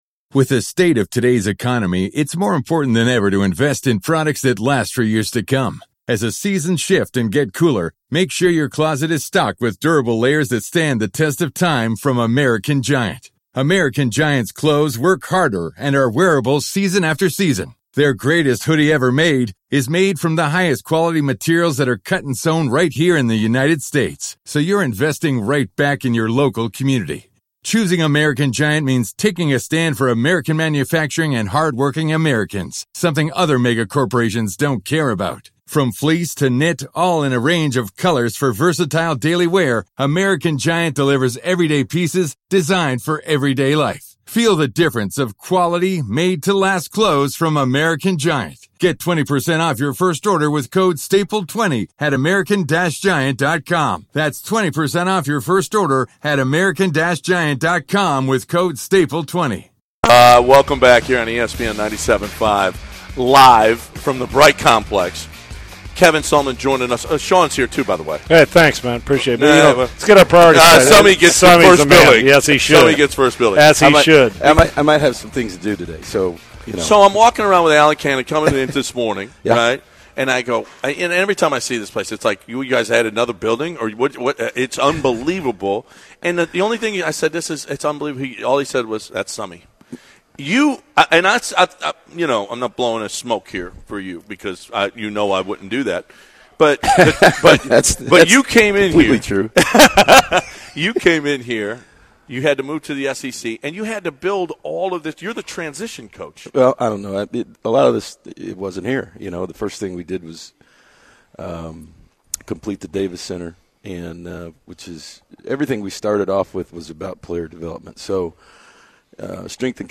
Kevin Sumlin Interview on Unfiltered